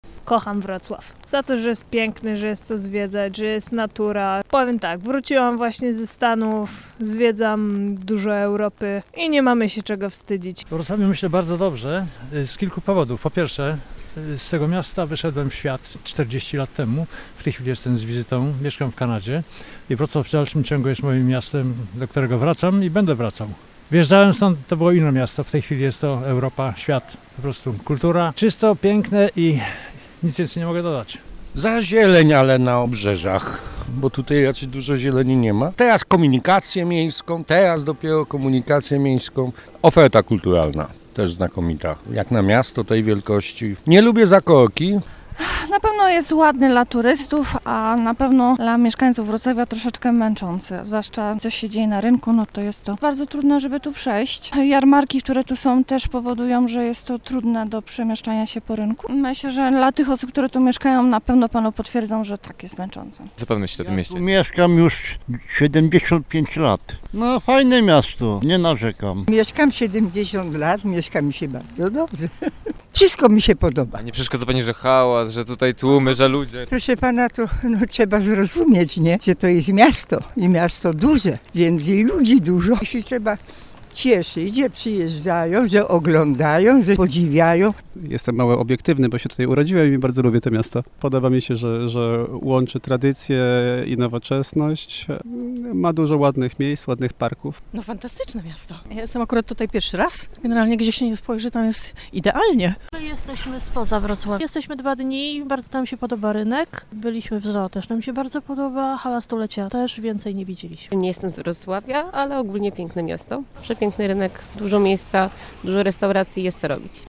Zapytaliśmy mieszkańców i gości, jak odbierają Wrocław.
sonda-o-wroclawiu.mp3